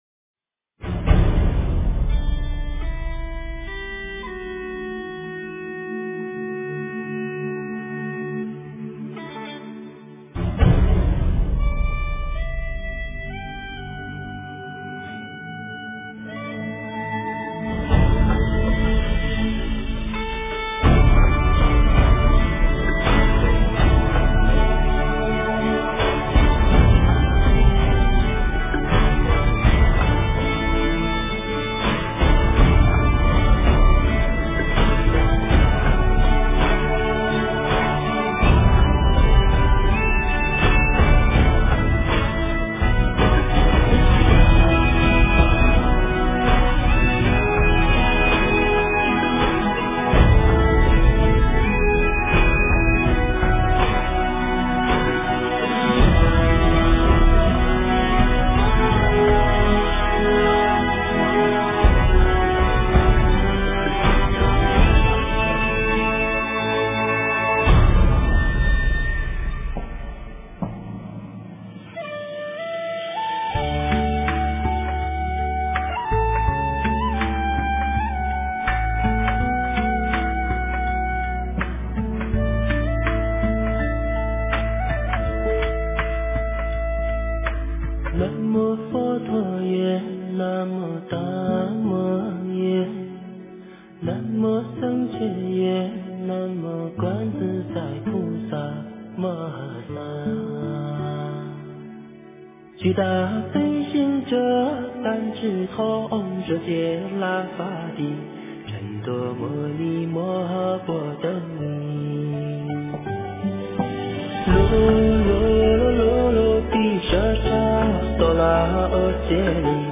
诵经
佛音 诵经 佛教音乐 返回列表 上一篇： 般若波罗密多心经 下一篇： 南无观世音菩萨 相关文章 地藏经-如来赞叹品第六 地藏经-如来赞叹品第六--佛经...